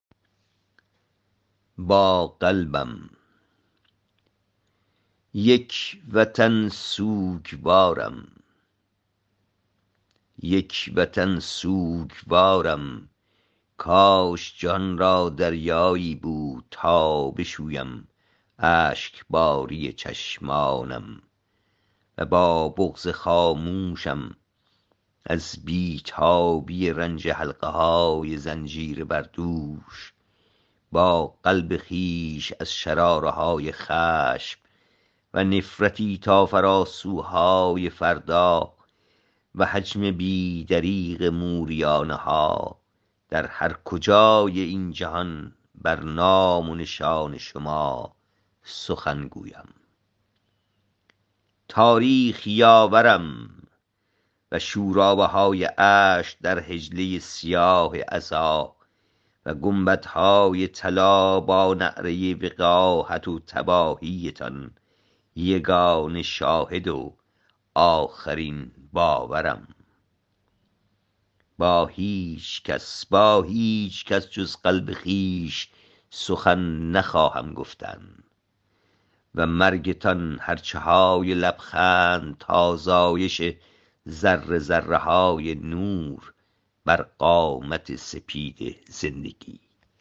این شعر را با صدای شاعر گوش کنید